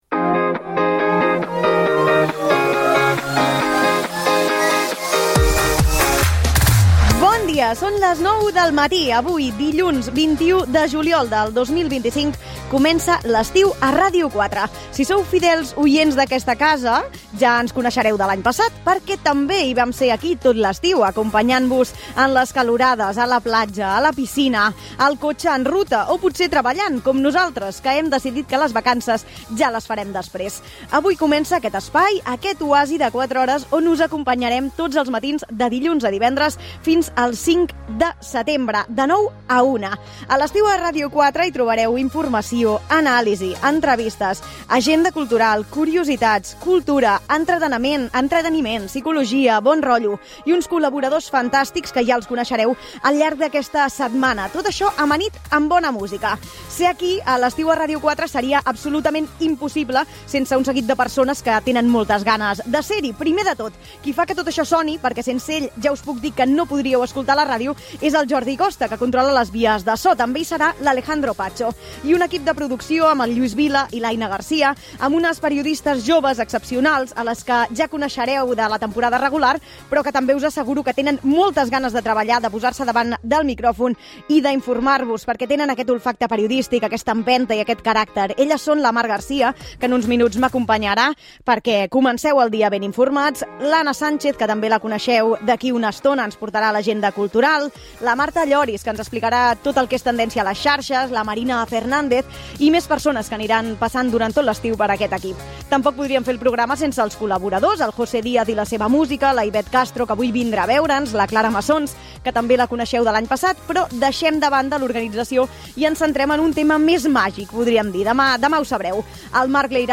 Sintonia, data, presentació de la primera edició de la segona temporada del programa, continguts, equip, indicatiu, estat de les carreteres, el temps, resum informatiu internacional, esports, tema musical, hora, indicatiu, agenda cultural Gènere radiofònic Info-entreteniment